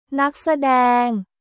ナック・サデーング